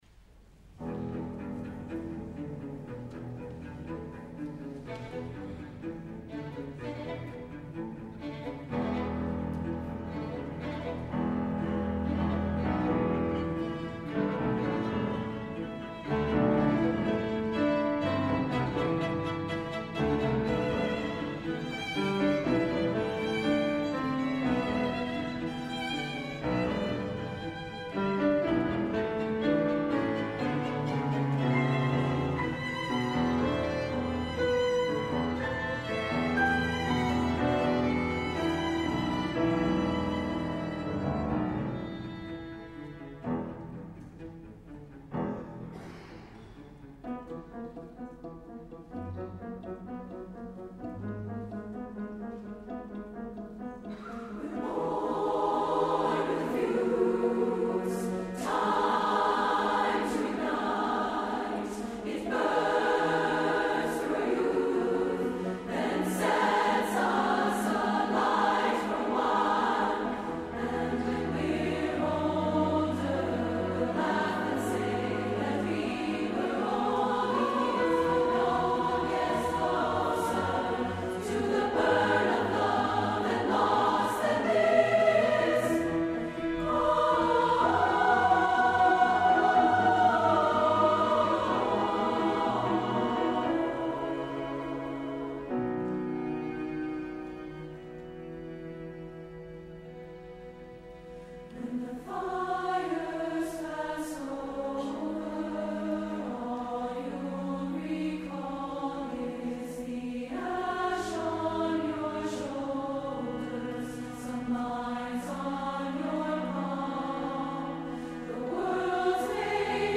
SSA, string quartet, piano
SSA choir, string quartet and piano
2 violins, 1 viola, 1 cello, piano